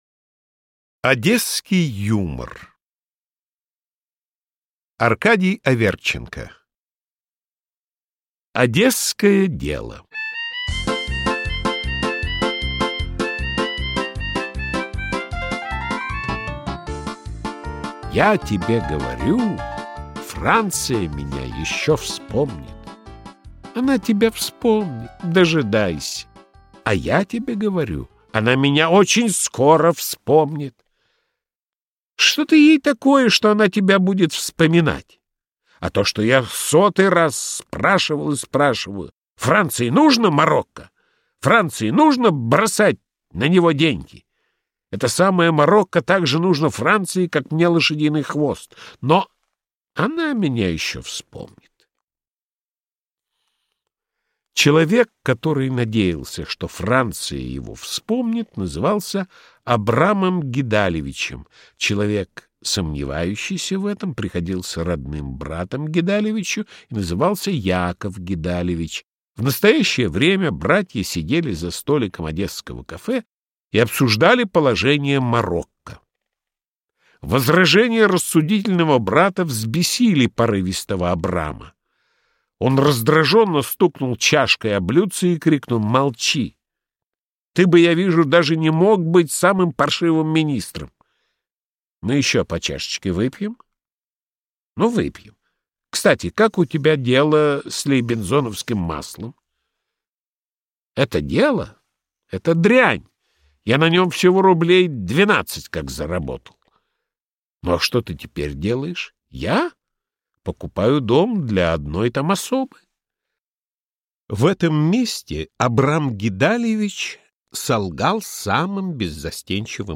Аудиокнига Одесский юмор | Библиотека аудиокниг